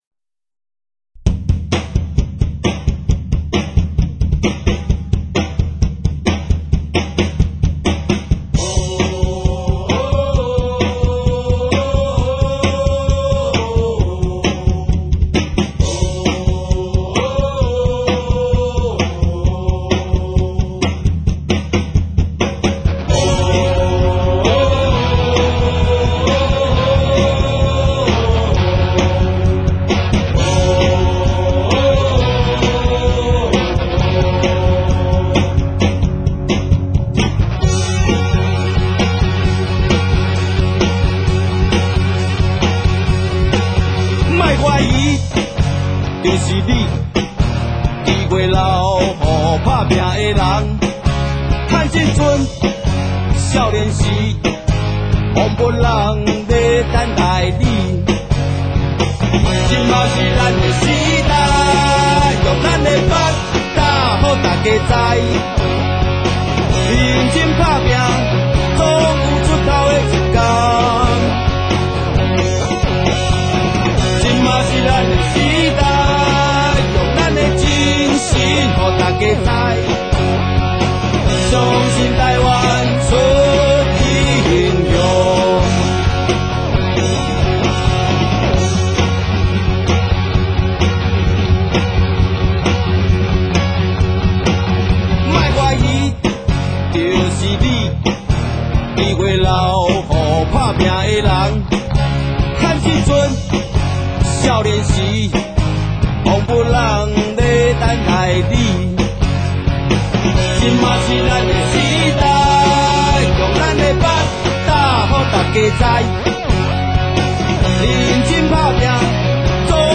演唱版